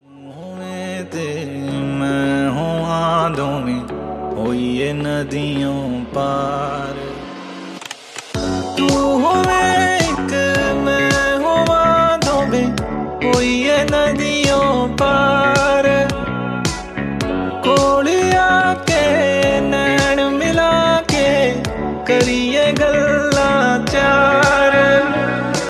romantic and soulful
Punjabi vibes